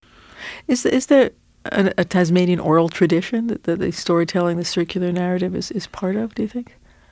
The deaccentuation of final sentence adverbials is, in any case, a very likely possibility, as the following examples show: